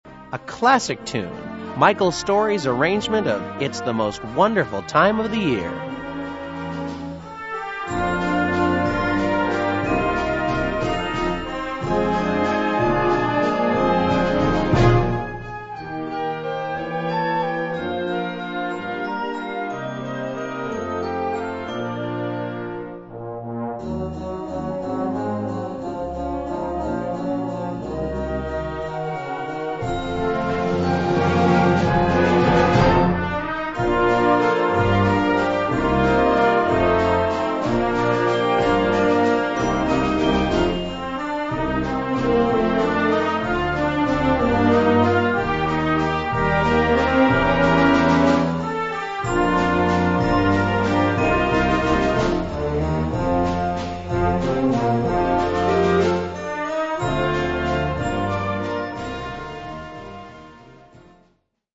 Besetzung: Blasorchester
Flowing and graceful